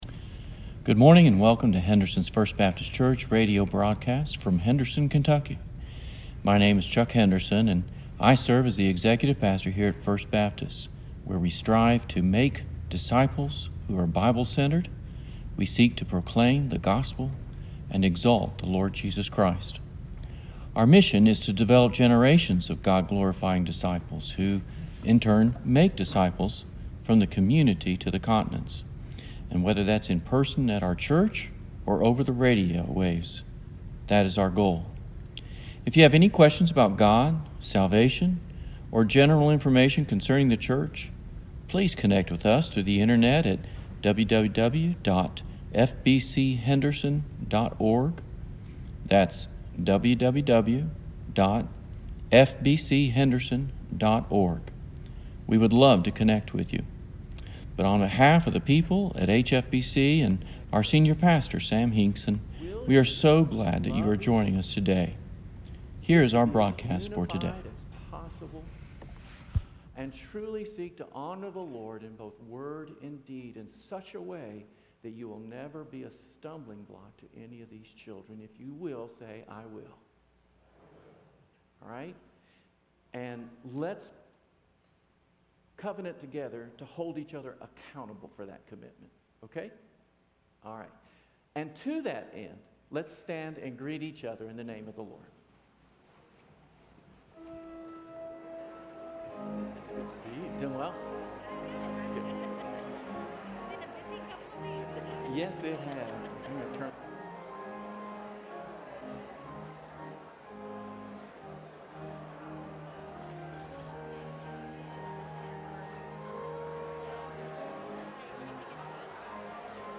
Father's Day